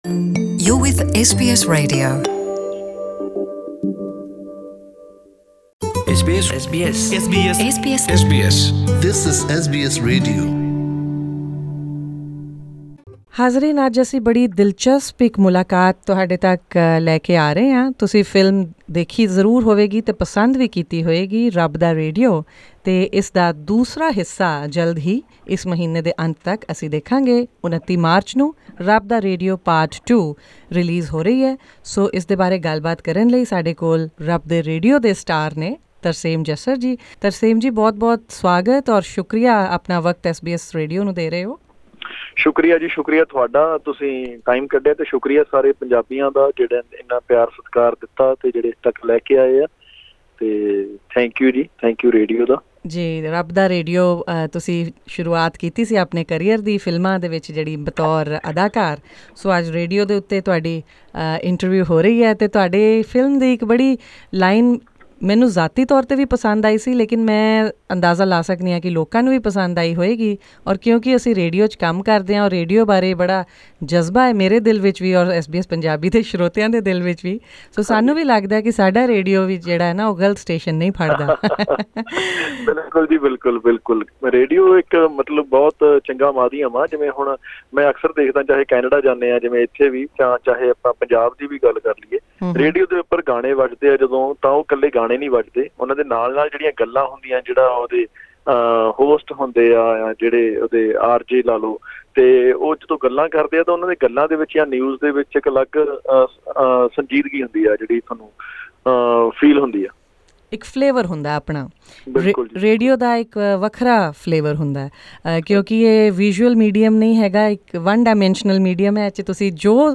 During a promotional tour to Australia, this Punjabi entertainer talks about how he views the power of radio, Punjabi language and of course, his upcoming film.
Speaking with SBS Punjabi , Mr Jassar shares the story of his journey and also talks about Rabb Da Radio 2 .